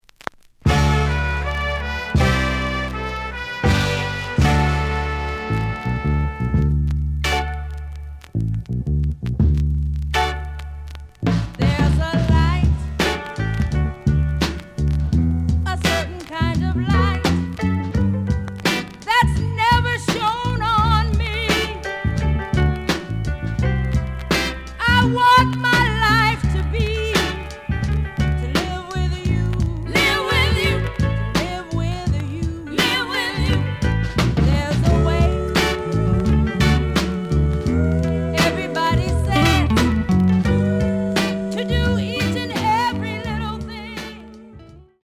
The audio sample is recorded from the actual item.
●Format: 7 inch
●Genre: Soul, 60's Soul